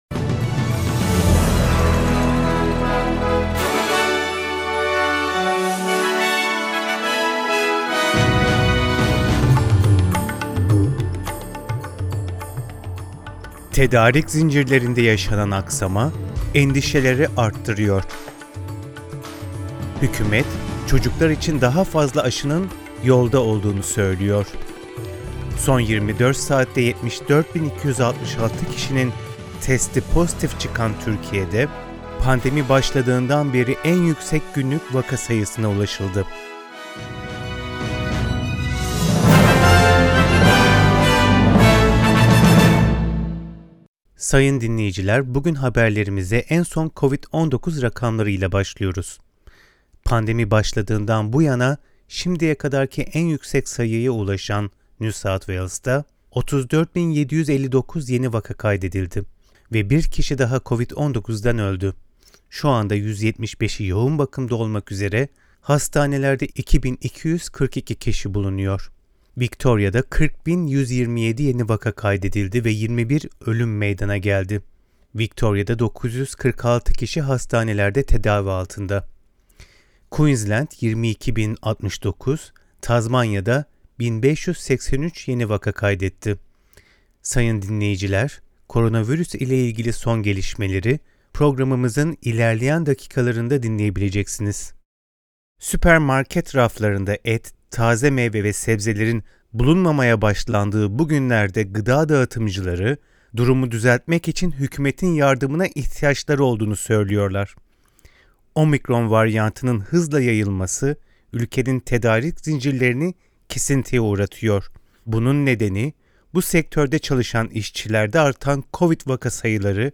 SBS Türkçe Haberler 12 Ocak